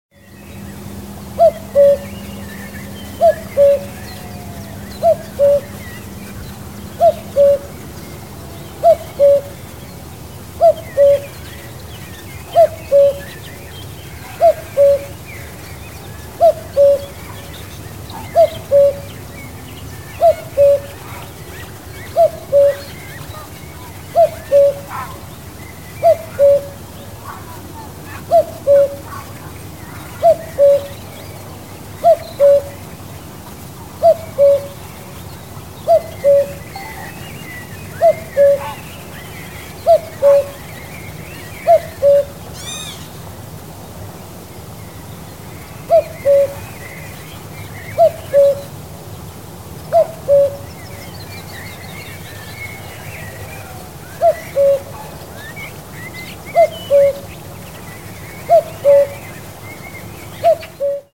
Common cuckoo sound singing call sound effects free download
About Common cuckoo sound singing call Mp3 Sound Effect